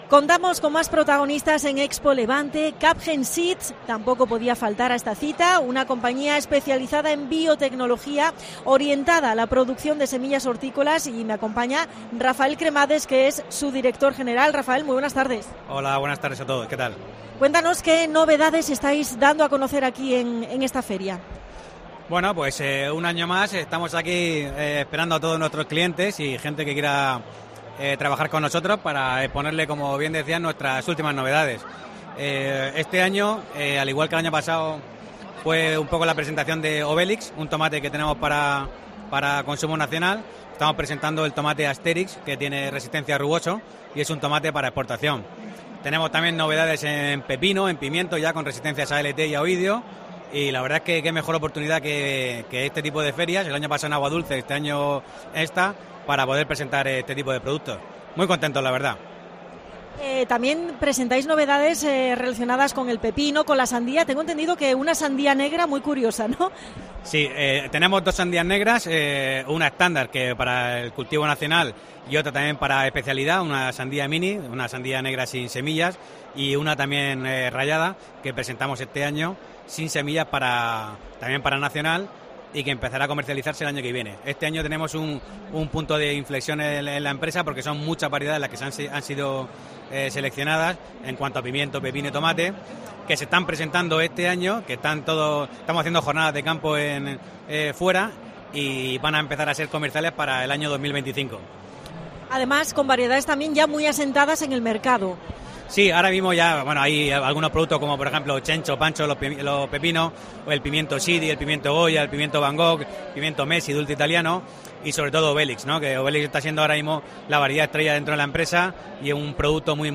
AUDIO: Especial ExpoLevante 2024 en COPE Almería. Entrevista